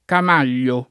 camaglio [ kam # l’l’o ] s. m.; pl. -gli